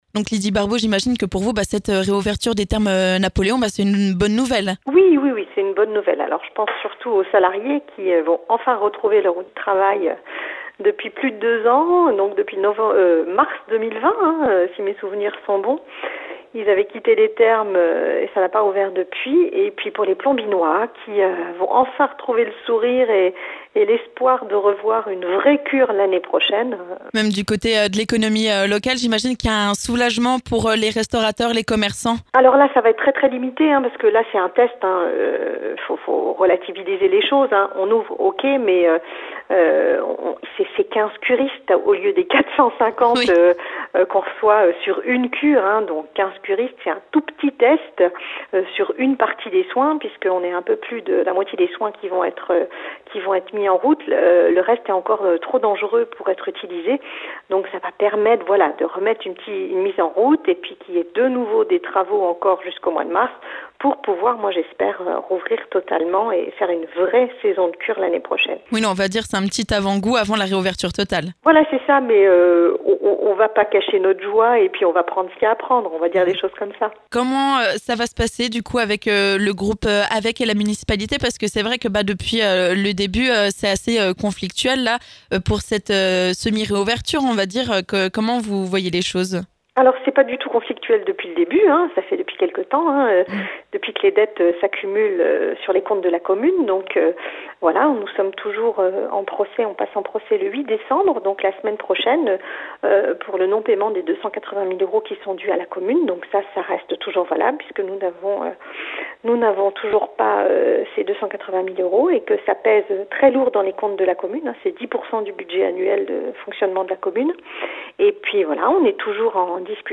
On en parle avec la Maire de Plombières-les-Bains, Lydie Barbaux !